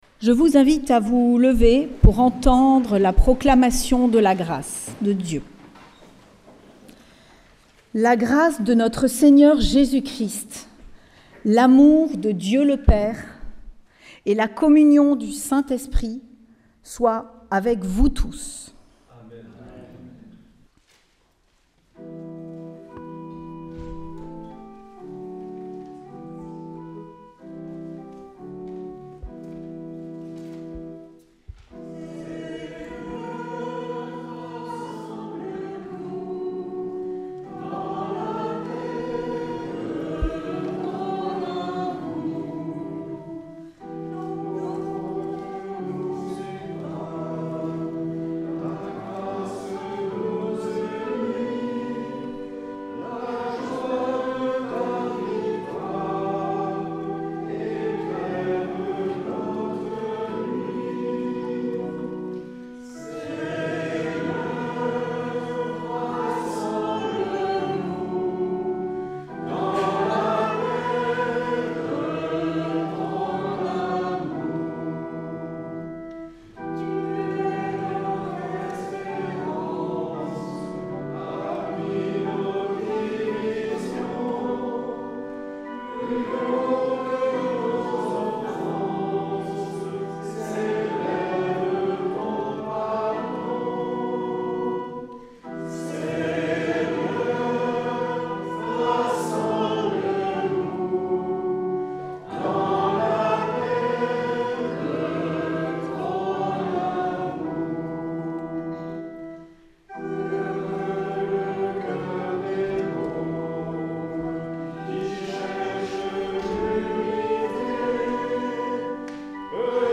Célébration oecuménique du 18 janvier 2024 au Temple de Bayonne
Vous pouvez revivre cette célébration et écouter la prédication de Mgr Marc Aillet. Merci à l’Eglise Protestante Unie de Bayonne pour son accueil.